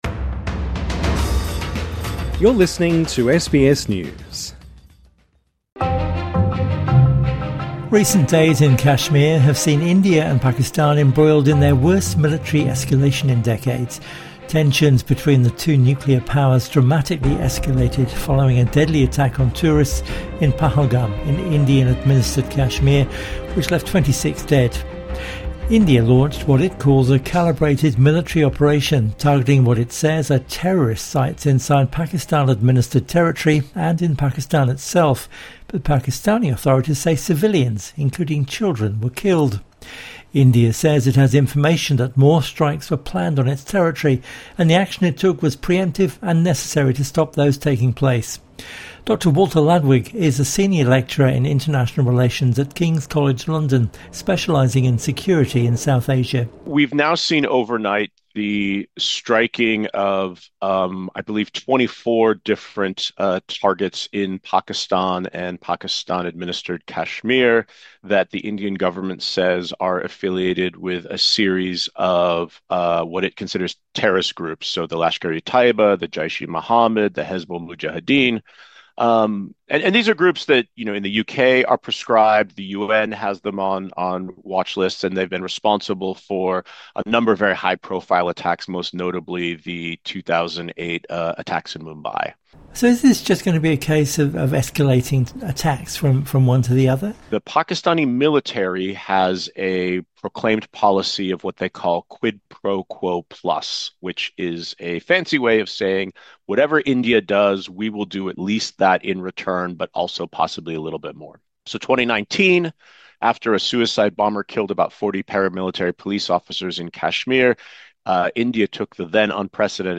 INTERVIEW: The conflict in Kashmir